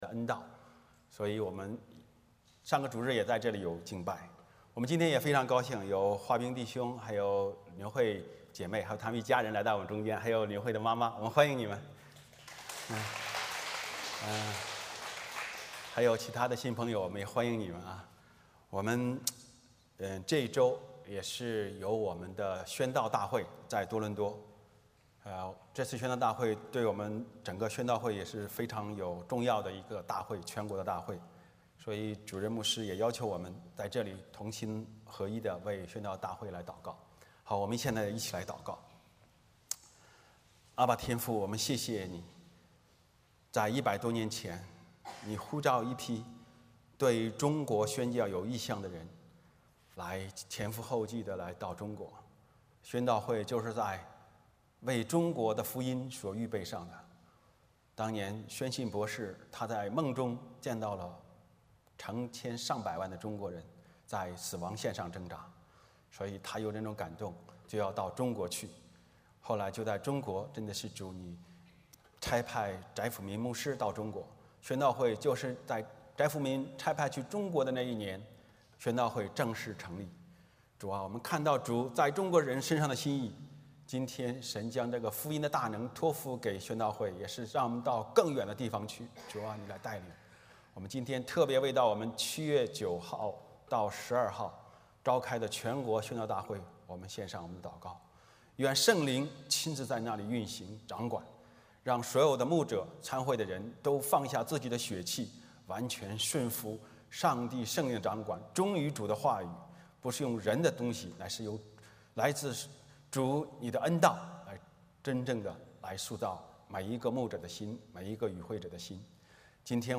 欢迎大家加入我们国语主日崇拜。